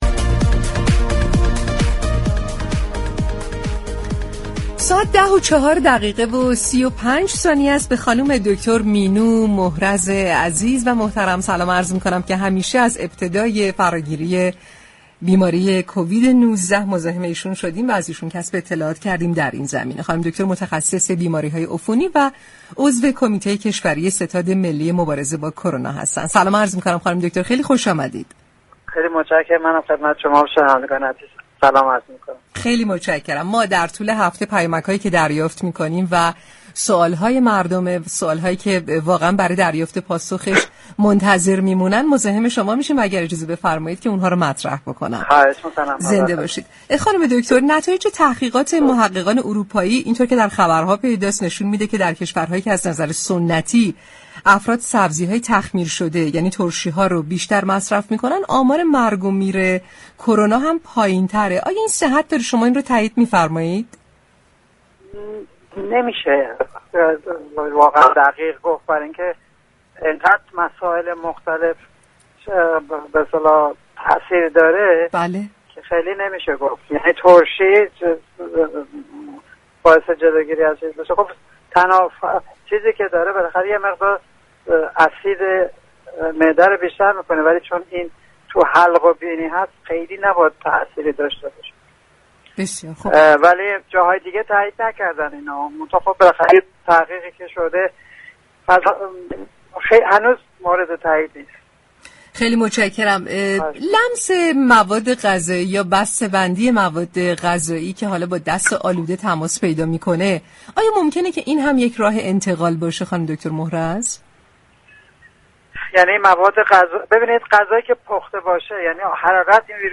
دكتر مینو محرز، متخصص بیماری‌های عفونی و عضو كمیته ملی كشوری ستاد مبارزه با كرونا در گفتگو با تهران كلینیك رادیو تهران به تأیید و یا تكذیب برخی از شایعات در رابطه با كرونا پرداخت.